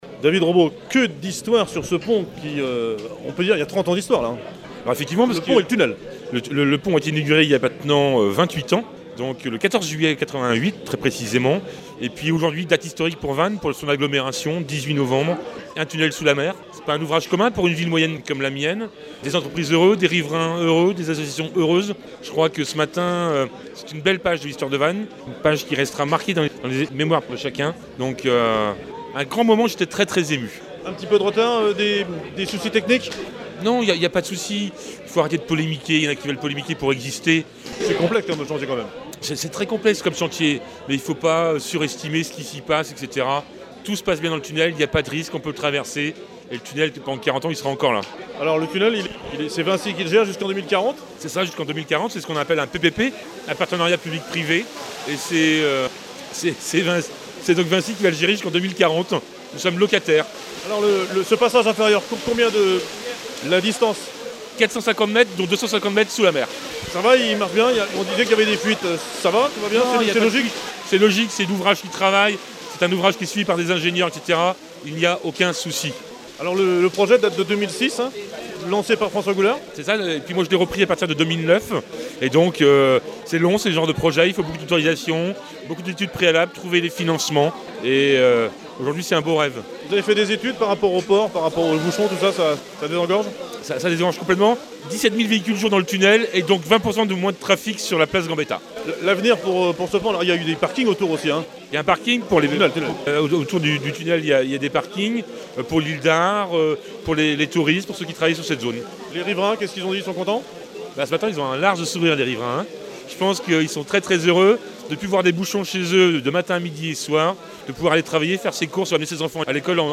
Tunnel de KERINO à Vannes (56) – Inauguration vendredi 18 novembre 2016  de cette ouvrage très utile – Passage inférieur sous la rivière La Rabine
Interview de François Goulard – Président du Conseil Départemental du Morbihan – Ancien Maire de Vannes à l’origine du projet du Tunnel
Interview de David Robo – Maire de Vannes et qui suivi le projet après François Goulard